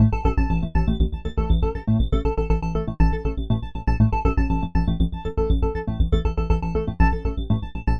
描述：带旋律的120bpm循环
Tag: 循环 120-BPM 击败